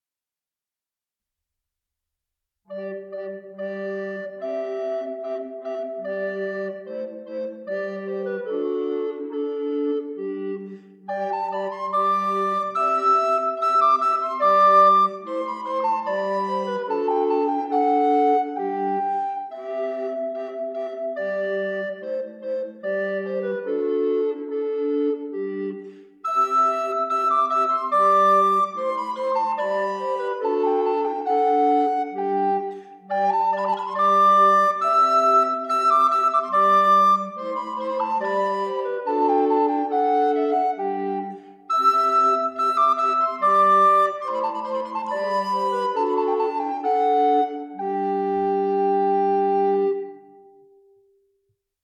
Ministriles coloniales de Guatemala
Música tradicional